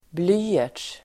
Uttal: [(²)bl'y:er_ts]